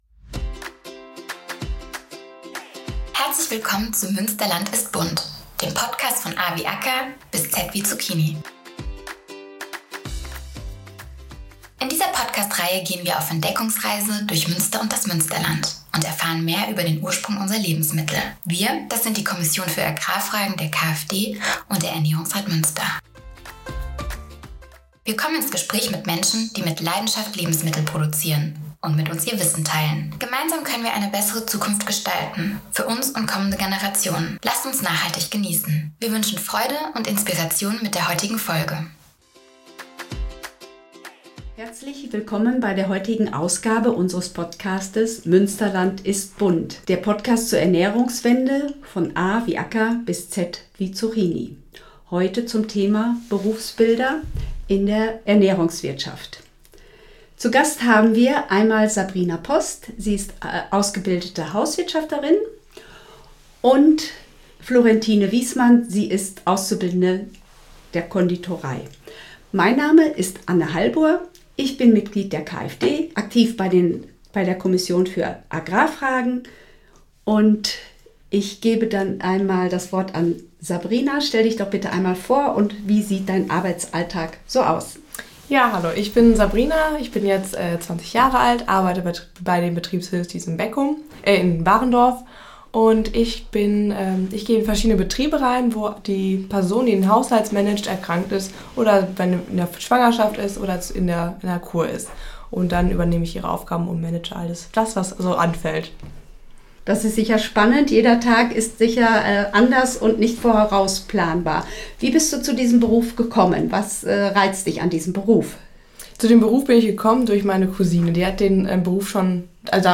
Sich zwischen tausenden von Ausbildungsberufen für den richtigen zu entscheiden, ist keine leichte Aufgabe. In diesem Berufsbildung Spezial sprechen wir mit einer Hauswirtin und angehenden Bäckerin, über die Besonderheiten, aber auch Schwierigkeiten ihrer Berufsfelder.